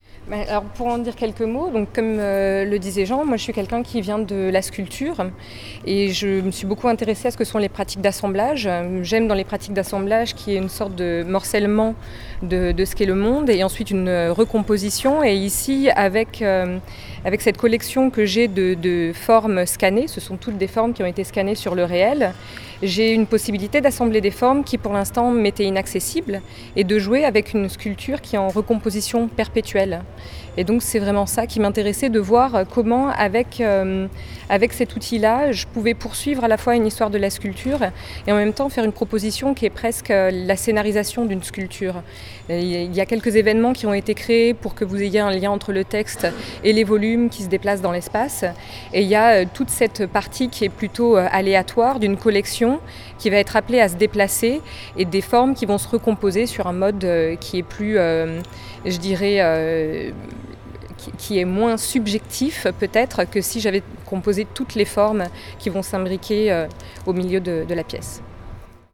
un reportage en images et en sons